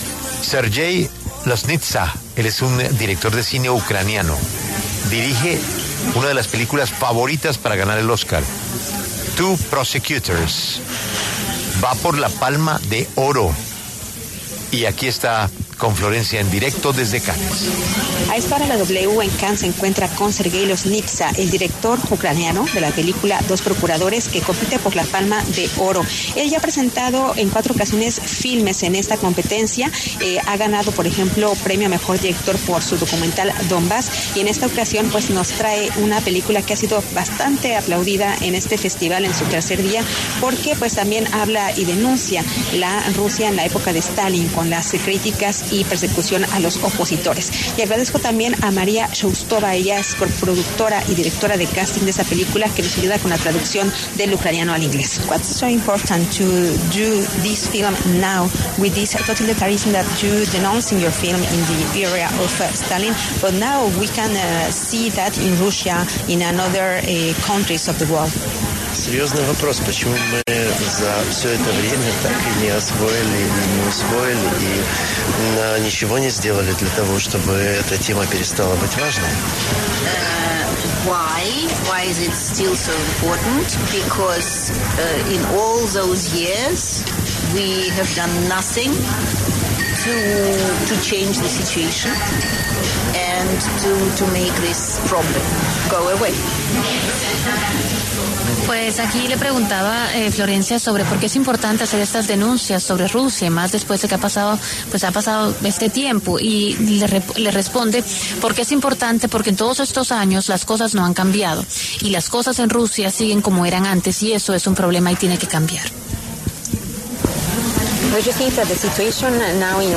Desde el Festival de Cannes, La W conversó con Sergei Loznitsa, director de cine ucraniano y de la película ‘Two Prosecutors’ que compite por la Palma de Oro y ha sido aplaudida.